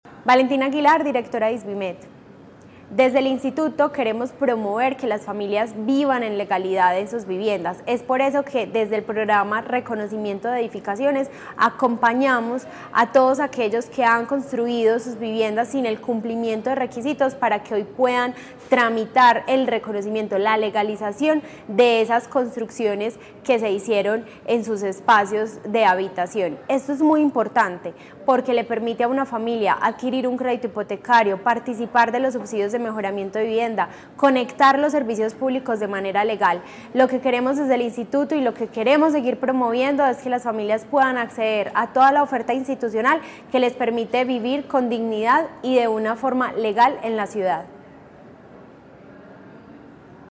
Declaraciones-directora-del-Instituto-Social-de-Vivienda-y-Habitat-de-Medellin-Isvimed-Valentina-Aguilar-Ramirez.mp3